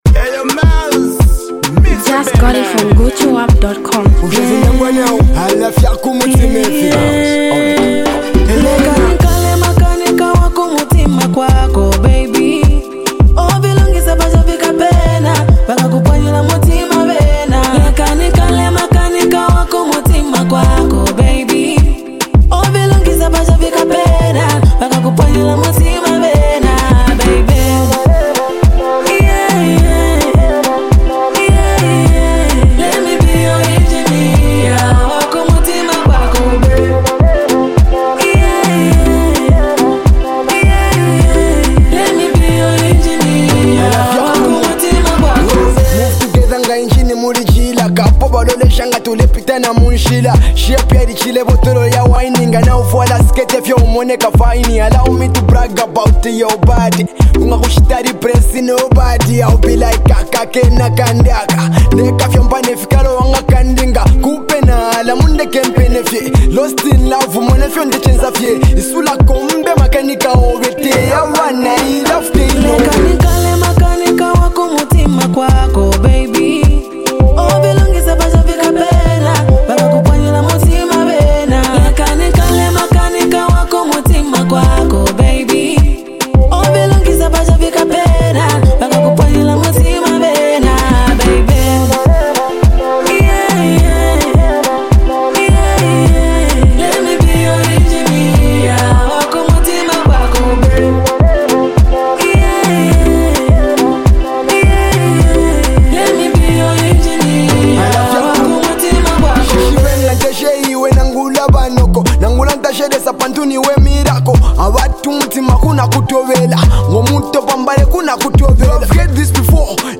powerful melodic hit jam